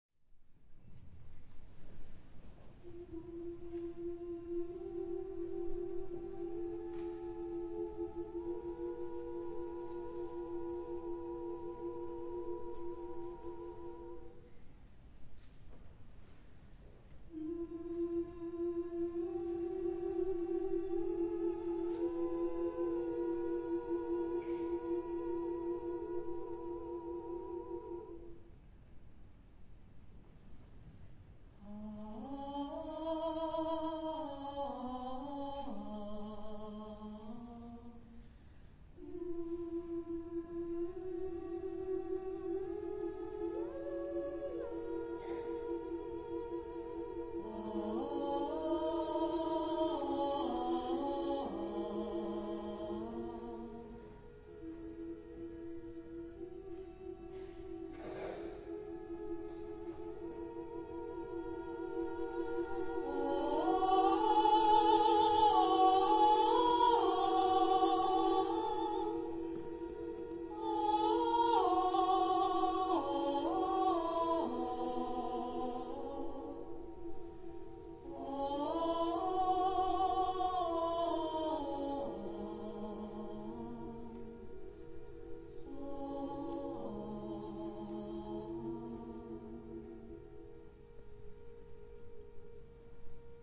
Genre-Style-Form: Contemporary ; Vocalise
Type of Choir: SSSAA  (5 women voices )
Soloist(s): Soprano (1)  (1 soloist(s))
Tonality: polytonal
sung by Héliade, Sète (F)
Discographic ref. : Florilège Vocal de Tours, 2005